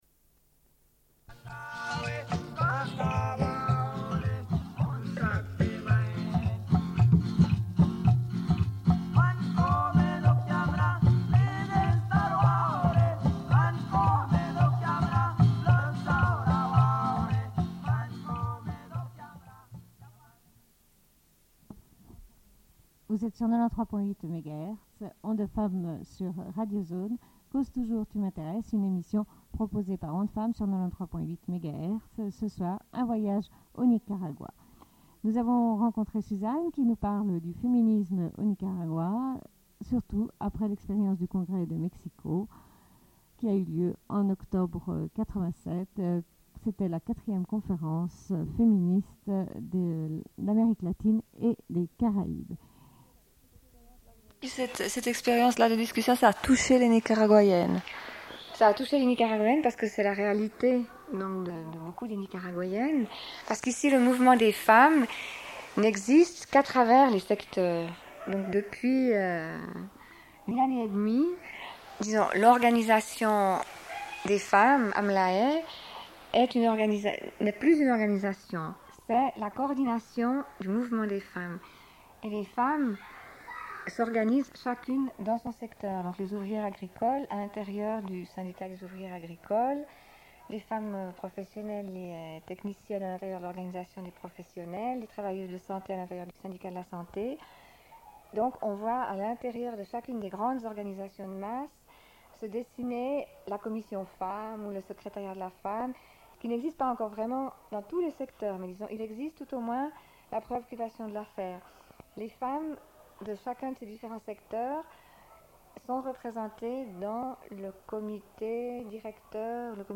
Une cassette audio, face B31:20